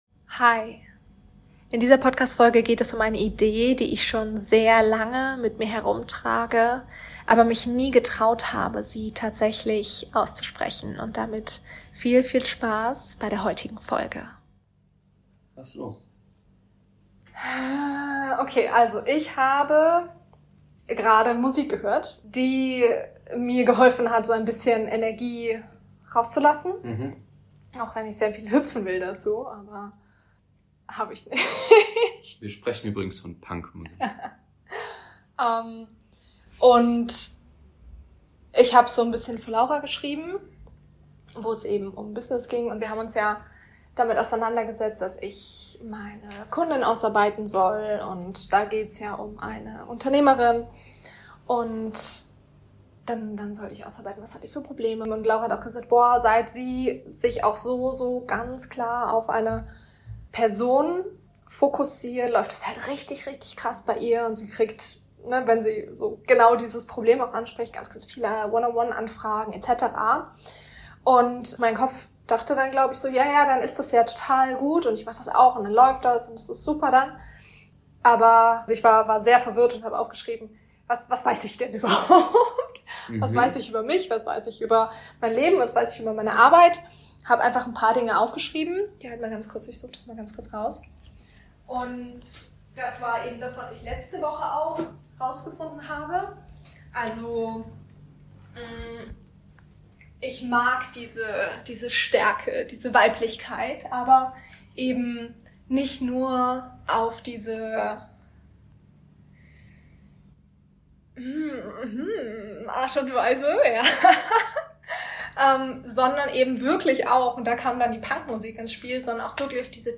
DISCLAIMER: Da es sich um einen authentischen Gesprächsmitschnitt handelt, hat der Ton keine Studioqualität.